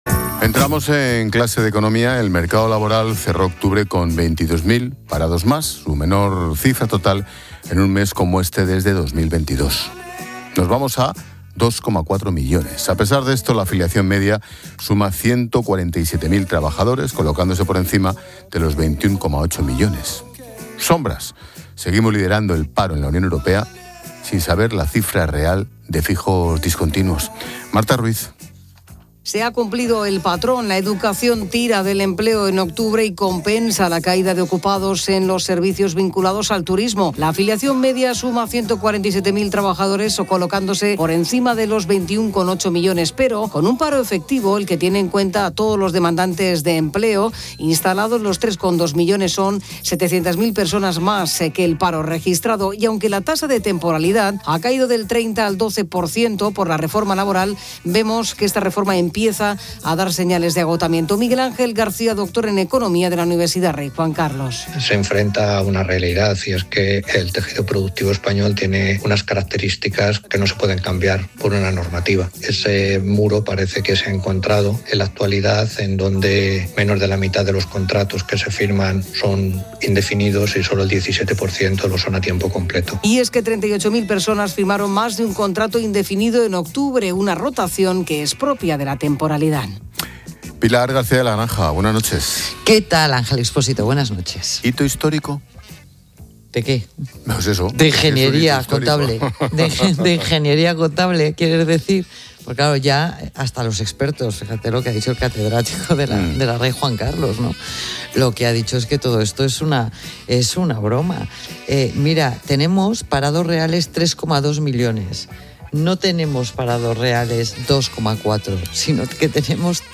Ángel Expósito analiza con la experta económica y directora de Mediodía COPE, Pilar García de la Granja, la situación del mercado laboral en España